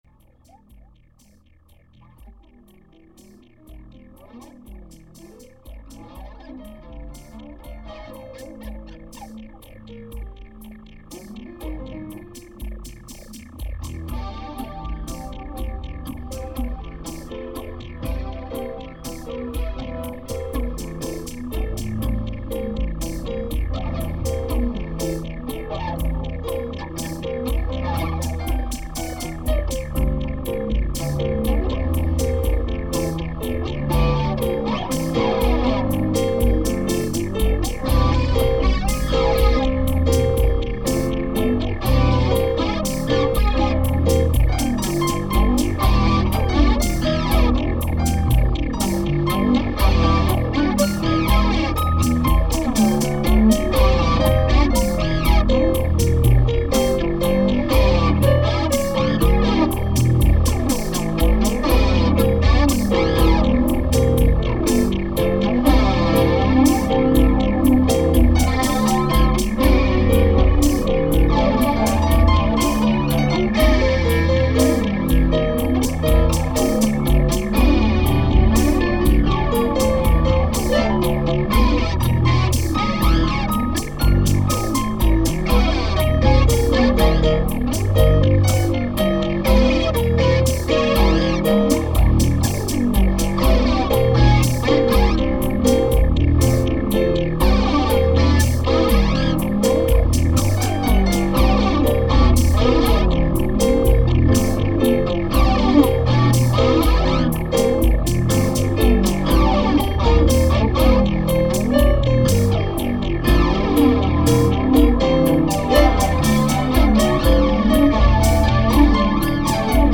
Tempo: 118 bpm / Datum: 08.02.2016